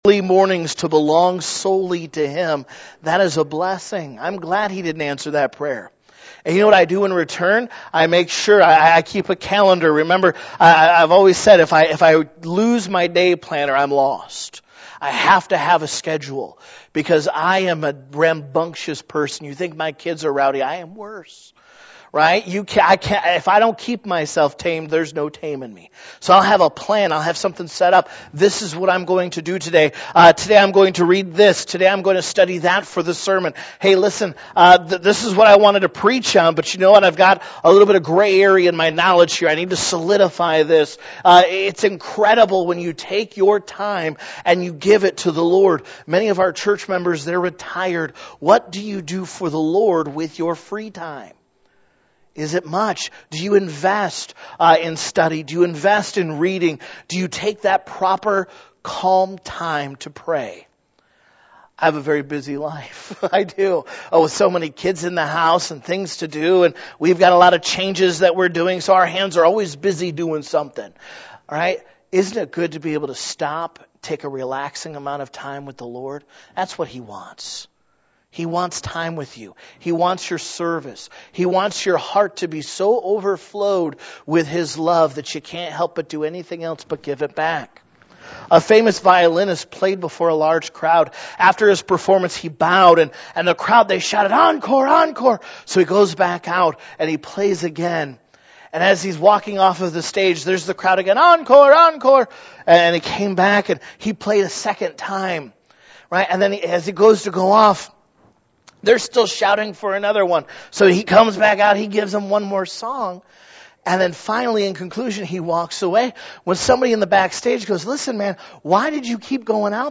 Home Baptist Church - 2021 Sunday School Services
February 2021 - Weekly Sunday Services This page presents the Sunday School lessons recorded at Home Missionary Baptist Church during our Sunday Services.